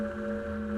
Drill/factory/generator noises